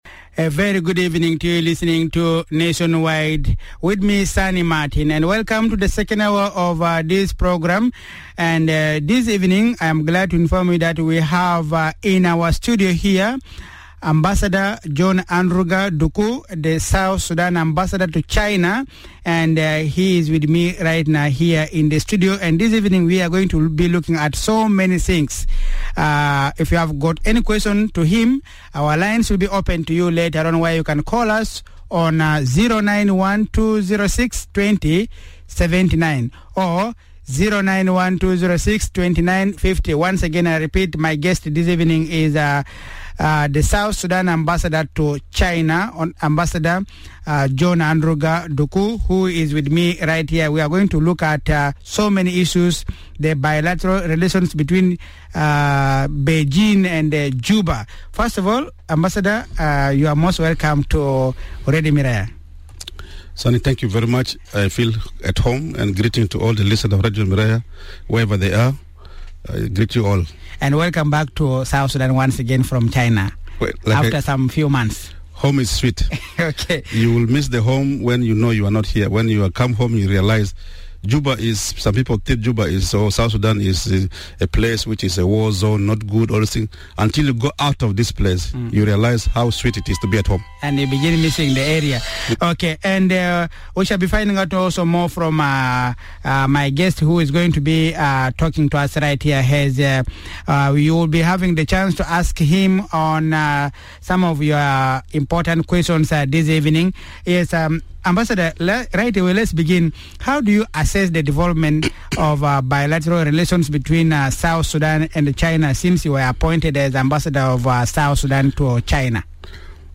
The Future of South Sudan - China Relations: A Discussion with Ambassador John Andruga Duku